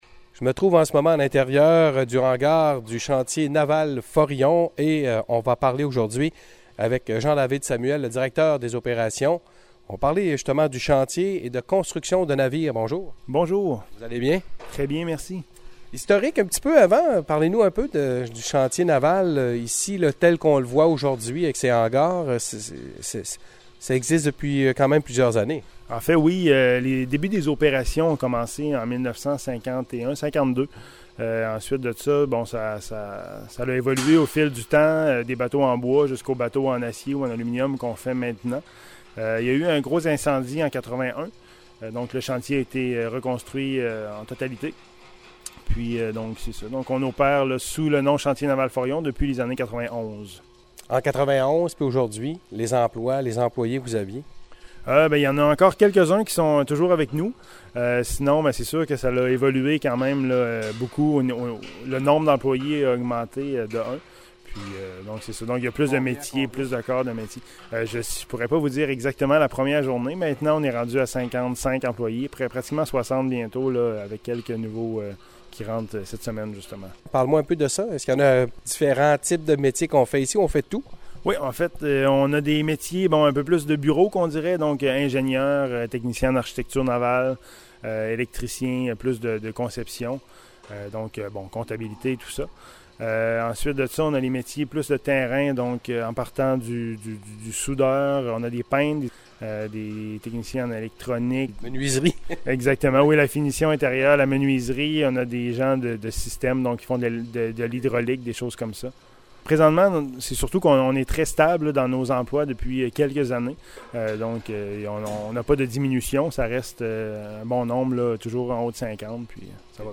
Écoutez nos entrevues réalisées lors de l'émission O'Leary le midi.
Lundi à l’émission O’Leary le midi, nous avons effectué une visite du Chantier Naval Forillon pour découvrir l’univers de la construction de navires.